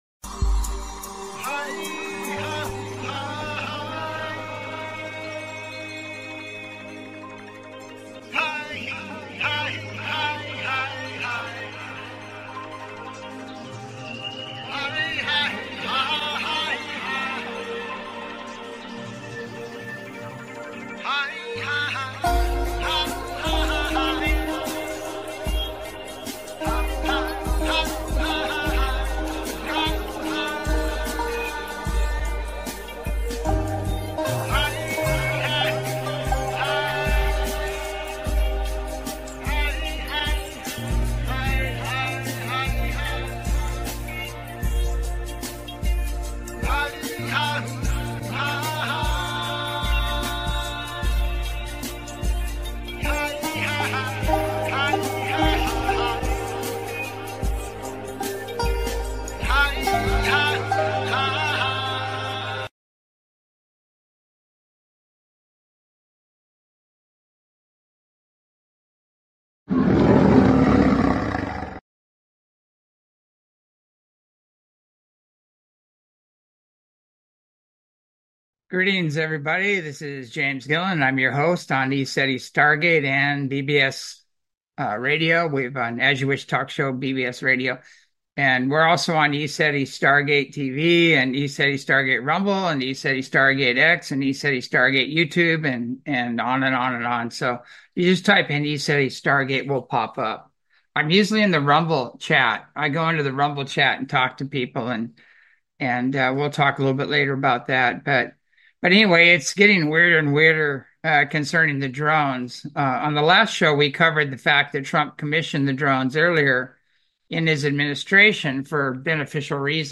Talk Show Episode, Audio Podcast, As You Wish Talk Radio and Name That Drone on , show guests , about Name That Drone, categorized as Earth & Space,News,Paranormal,UFOs,Philosophy,Politics & Government,Science,Spiritual,Theory & Conspiracy